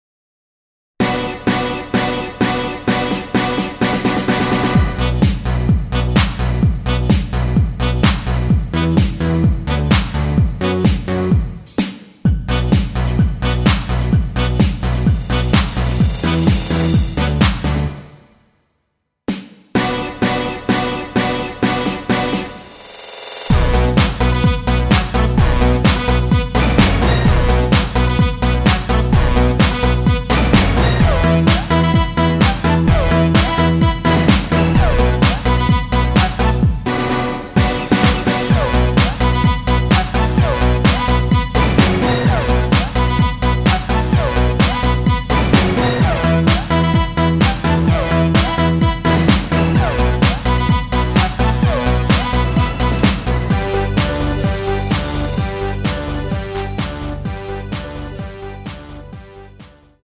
Bm
◈ 곡명 옆 (-1)은 반음 내림, (+1)은 반음 올림 입니다.
앞부분30초, 뒷부분30초씩 편집해서 올려 드리고 있습니다.